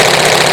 acf_engines